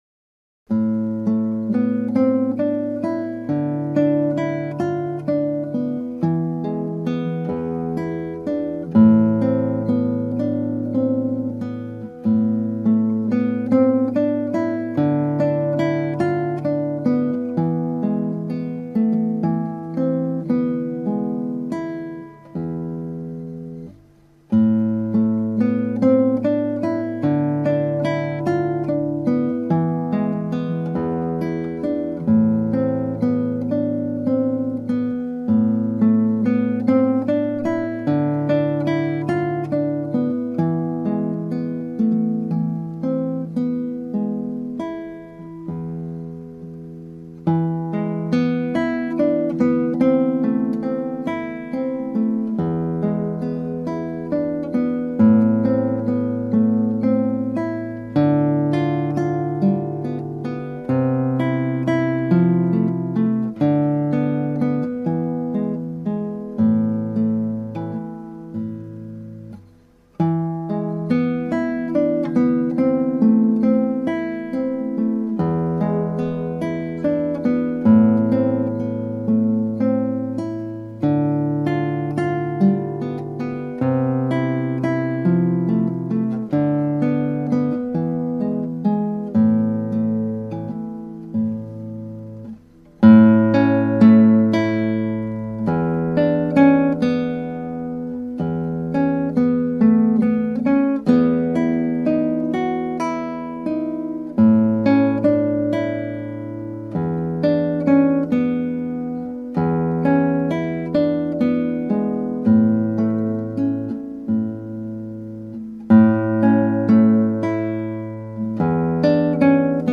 (アマチュアのクラシックギター演奏です [Guitar amatuer play] )
まず6/8拍子のリズムを体得します。
単音によるメロディの曲ですがいろいろ表現のアイディアが出てきます。